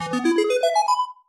На этой странице собраны энергичные звуки победы и выигрыша — от фанфар до коротких мелодичных оповещений.
Игра выиграна game won